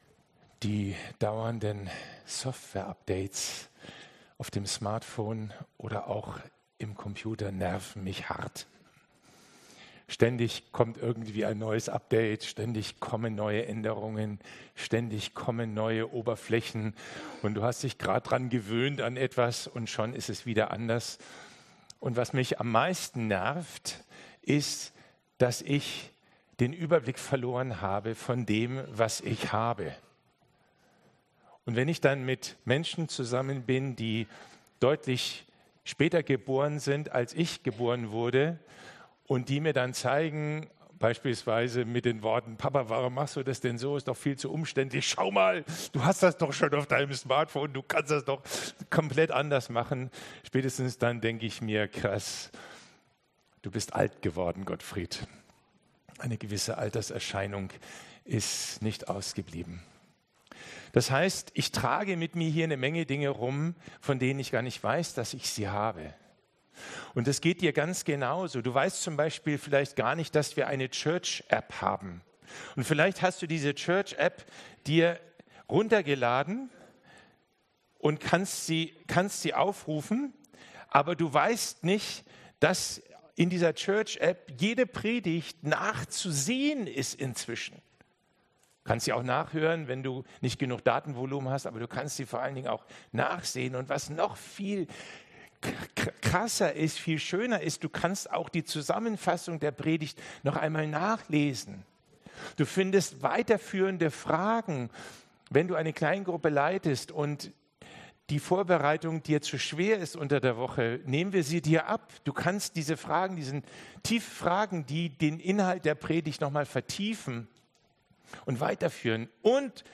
Predigt-Zusammenfassung